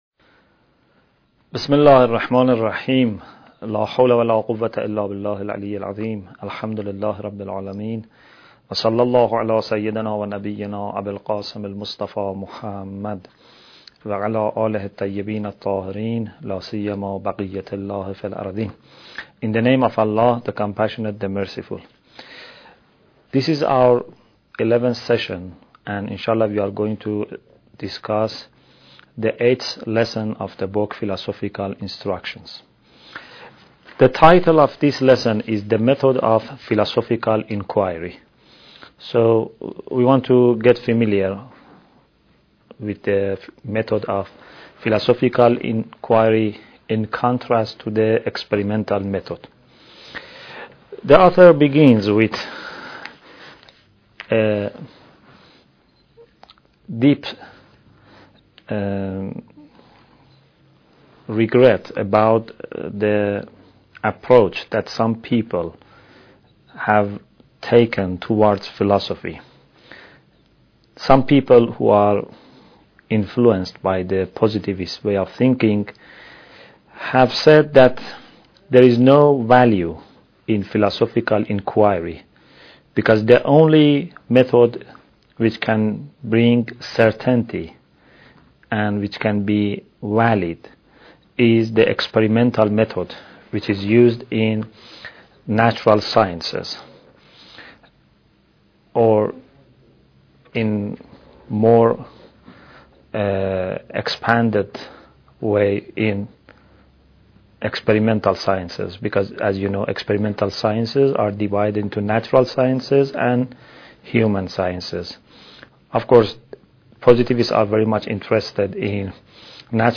Bidayat Al Hikmah Lecture 11